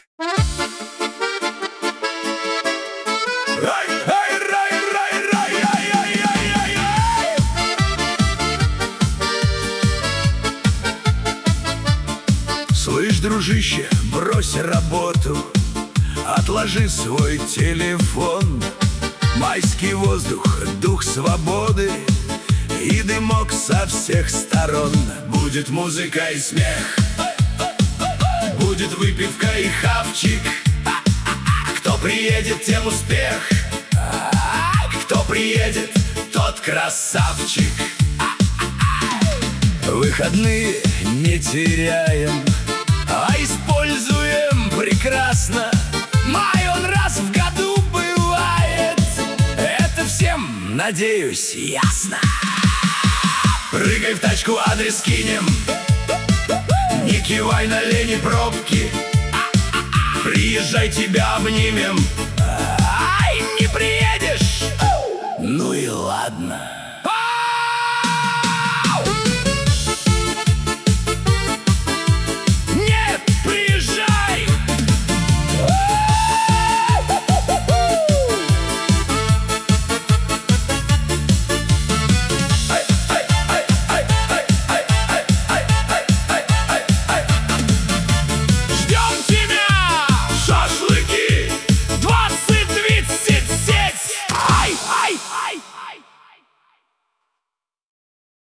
Оригинальное приглашение на пикник Пригласительная песня на шашлыки: создание трека через ИИ Шаг 1.